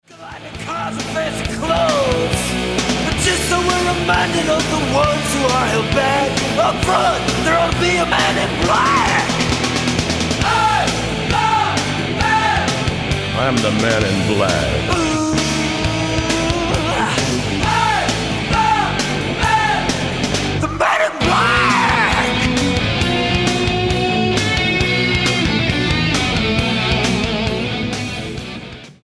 Not thrash, not speed, but serious noise with a message
Style: Hard Music